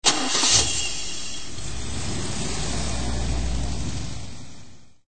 carsound.ogg